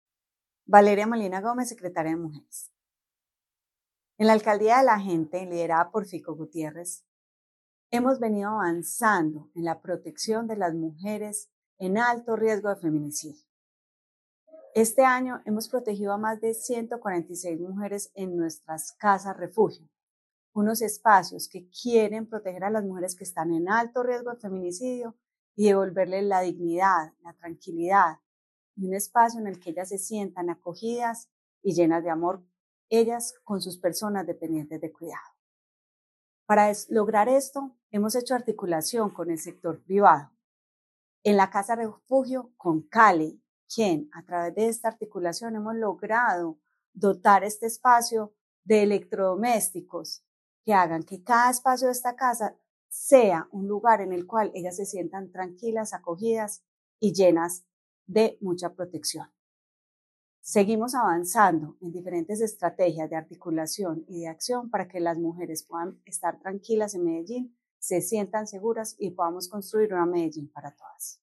Declaraciones de la secretaria de las Mujeres, Valeria Molina.
Declaraciones-de-la-secretaria-de-las-Mujeres-Valeria-Molina.-Dotacion-de-electrodomesticos.mp3